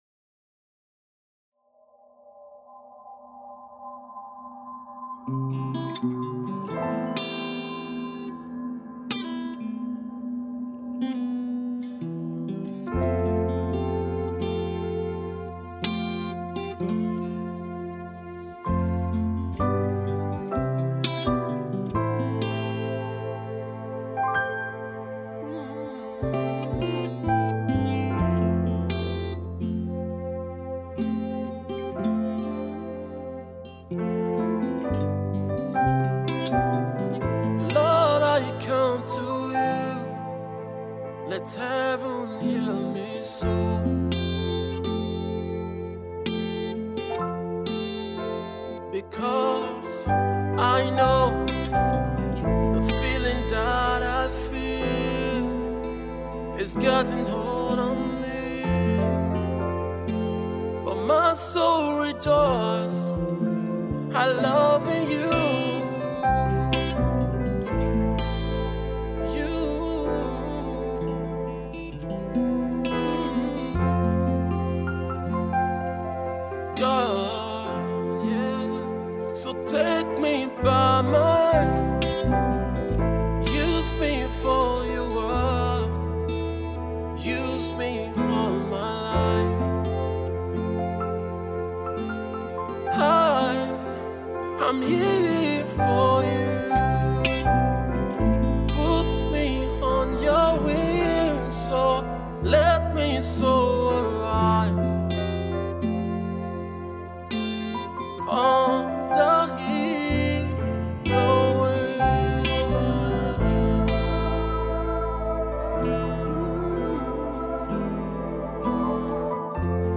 Inspirational song